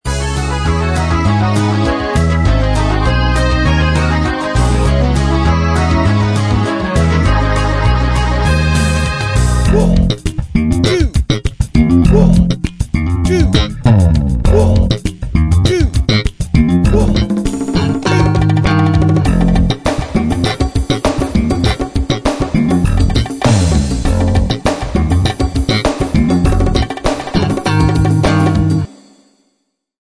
Fast keyboard work in the beginning. Very funky at the end!